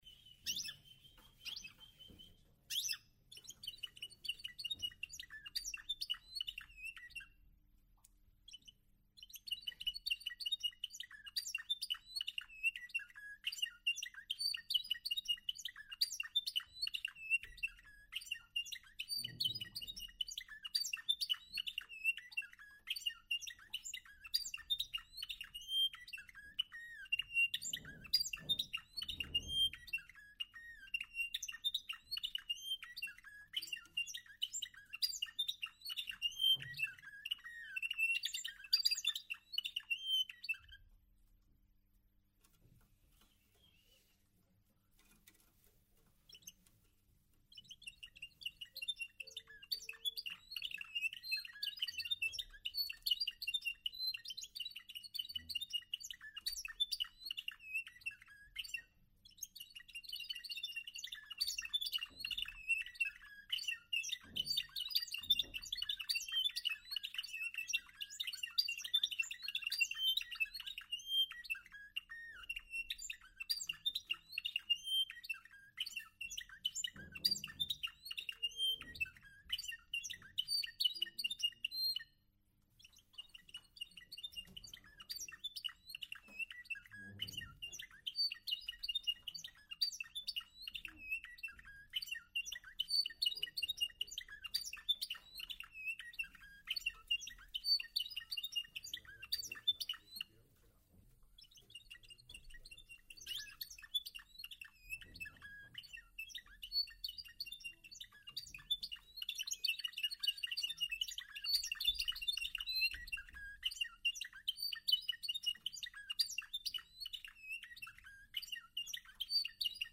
Algunos de los audios del XXXIV concurso de Canario de Canto Español Discontinuo celebrado por la Sociedad Lucense de Canaricultura en enero de 2017: